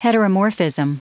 Prononciation du mot heteromorphism en anglais (fichier audio)
heteromorphism.wav